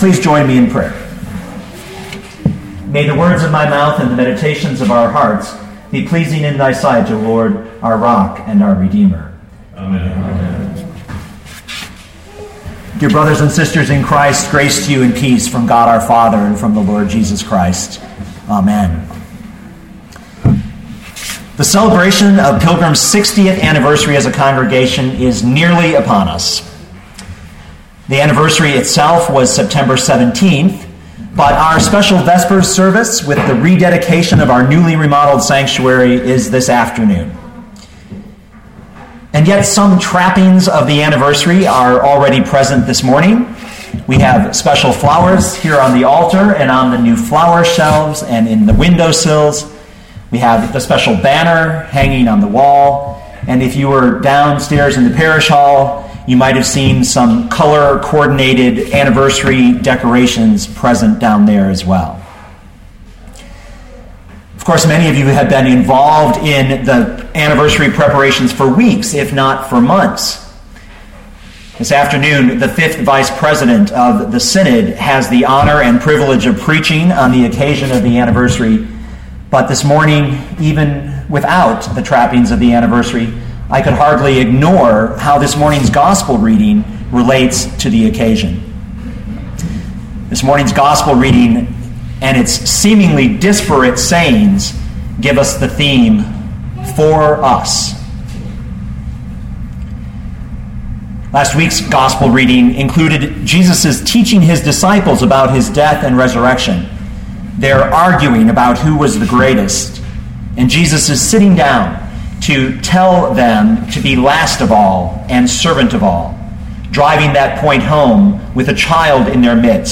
2012 Mark 9:38-50 Listen to the sermon with the player below, or, download the audio.